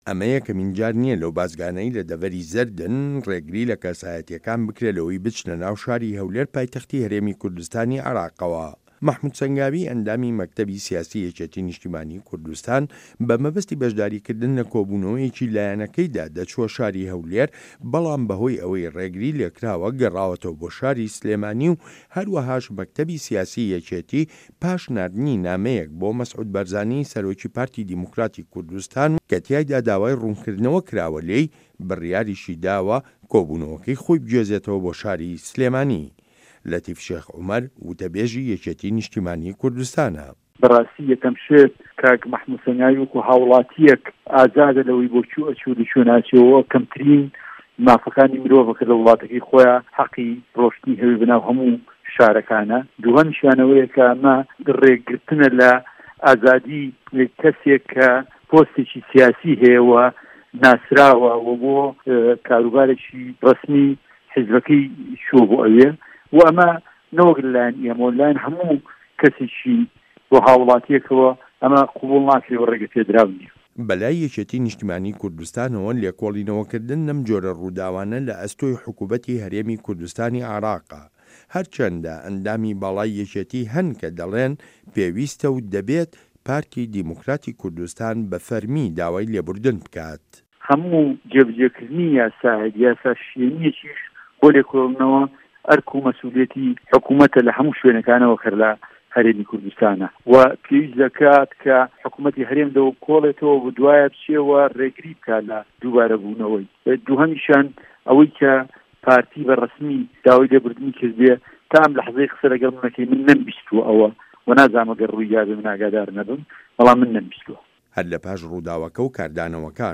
ڕاپۆرتی بازگەکانی هەرێمی کوردستان و دیاردەی ڕێگەگرتن لە خەڵک